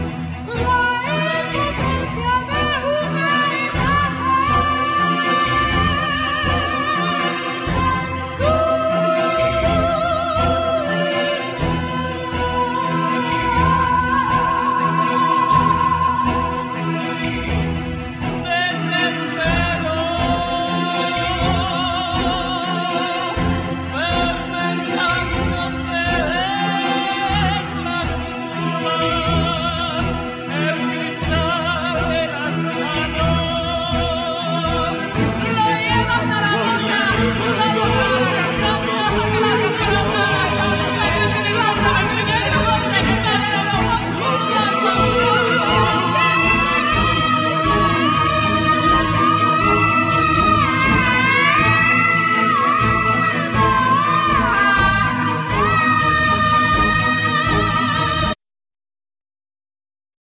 Saxphone,Machines
Flamenco guitar,Palms
Lead & Backing Vocal
Zapateados,Palms